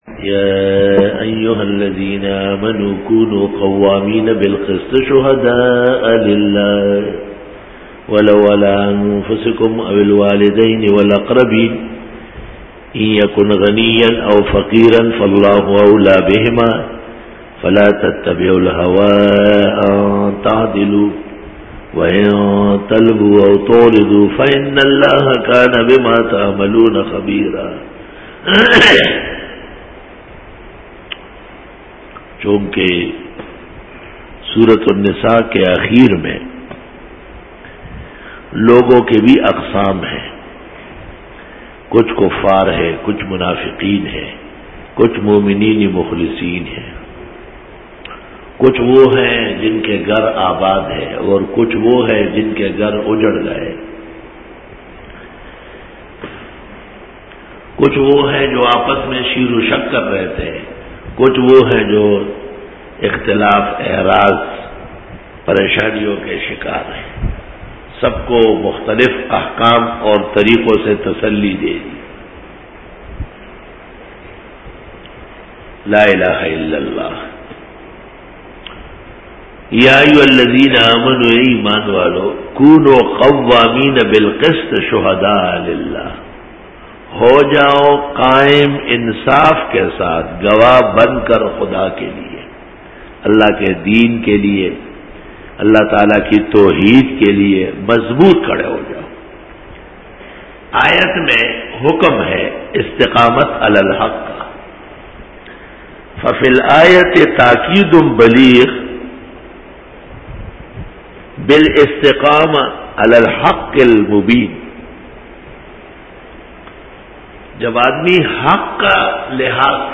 Dora-e-Tafseer 2009